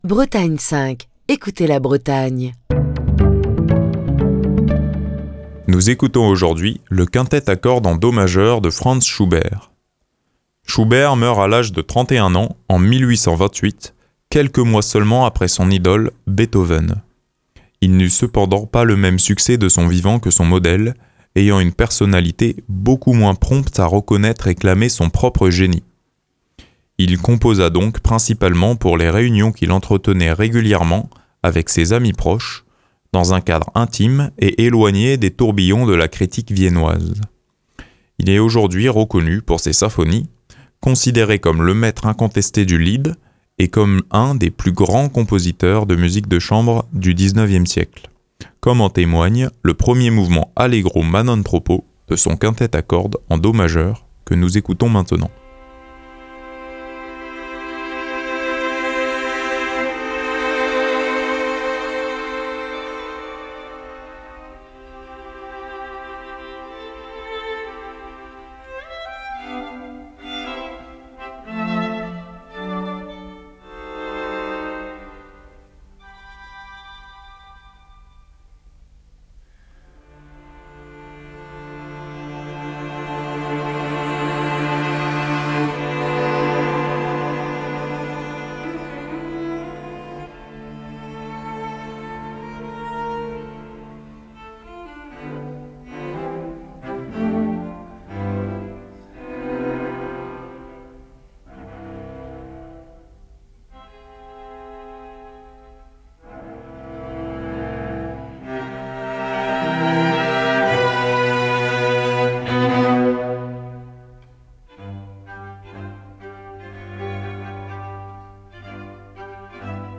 musique de chambre
Le premier mouvement du quintette à cordes en do majeur de Franz Schubert, un incontournable de la musique de chambre, écrit pour deux violons, un alto et deux violoncelles.
L’ajout d’un second violoncelle permet de tirer la sonorité vers des registres plus graves ce qui donne à l’ensemble des accents dramatiques.